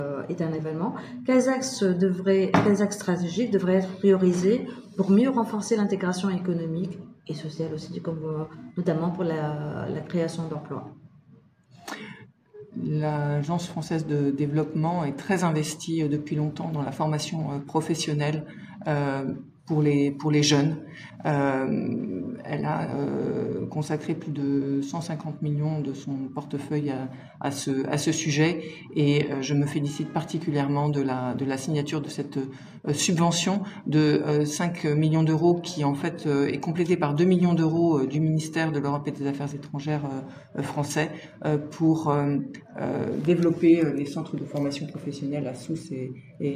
Anne Guéguen ambassadrice de France en Tunisie